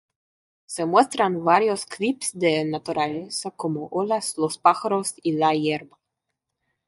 Pronounced as (IPA) /ˈpaxaɾos/